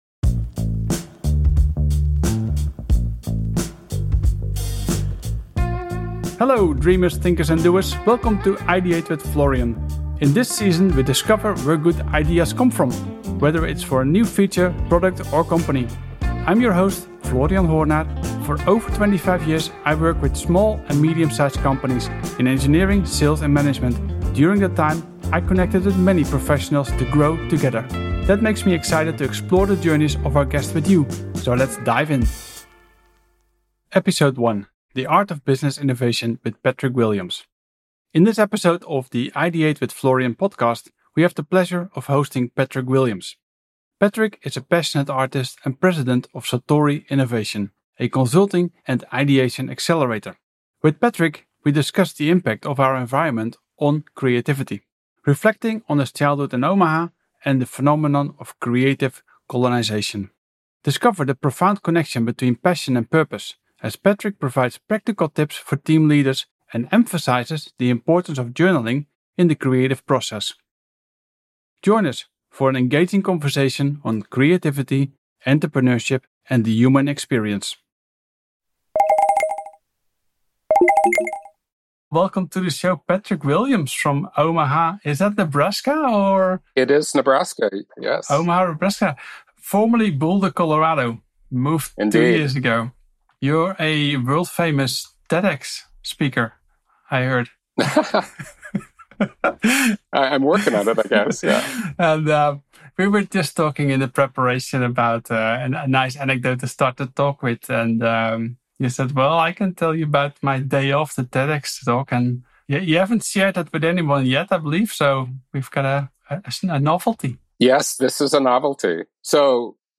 Join us for an engaging conversation on creativity, entrepreneurship, and the human experience.